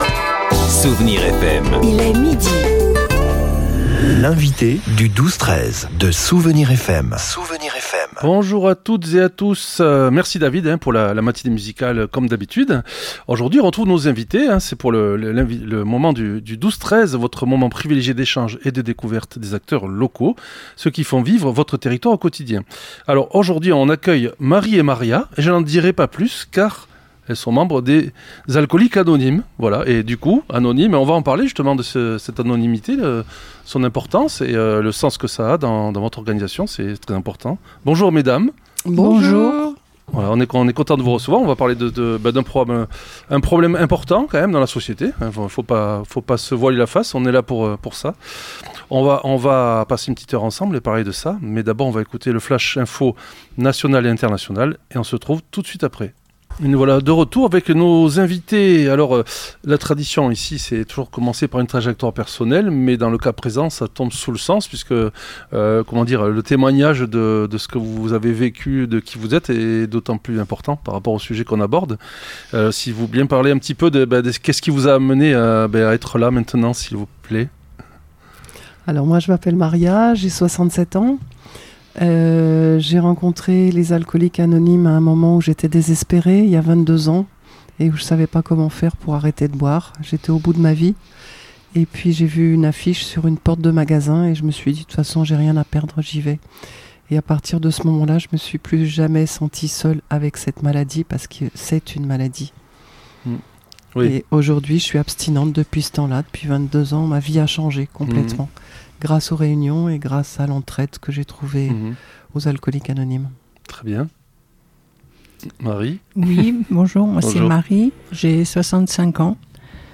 Nos deux invitées ont partagé avec nous l’enfer qu’elles ont traversé pour se libérer de l’alcool, un combat qu’elles ont remporté grâce aux Alcooliques Anonymes. Bien loin des clichés hollywoodiens, elles nous ont décrit des réunions empreintes d’humanité, où le rire a sa place – une lumière bien méritée après des années de lutte.